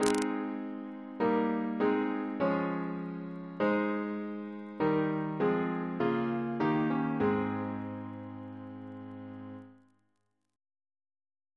Single chant in A♭ Composer: Rt Rev Thomas Turton (1780-1864), Dean of Peterborough and Westminster, Bishop of Ely Reference psalters: OCB: 83